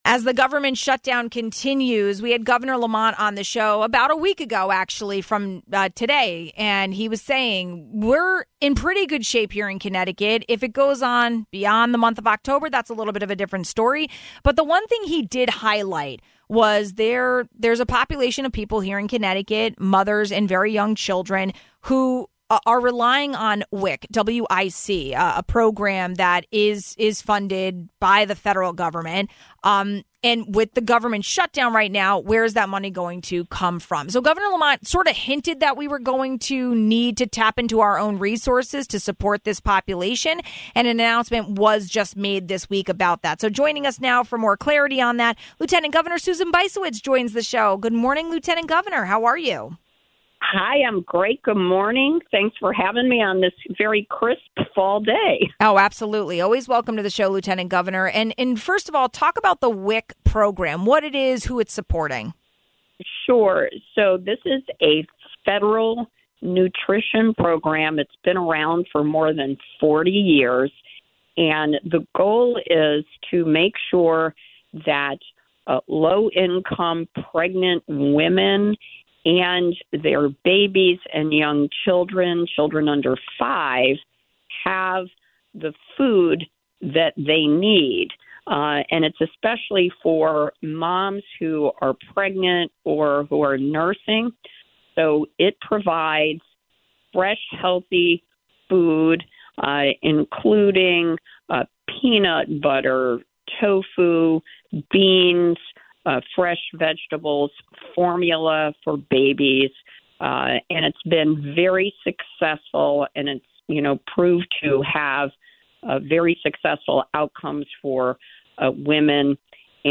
We did a check-in with Lt. Governor Susan Bysiewicz to talk about the WIC program, support for local seniors and the CT Grown for CT Kids program.